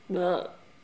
Les sons ont été découpés en morceaux exploitables. 2017-04-10 17:58:57 +02:00 144 KiB Raw History
beurk_02.wav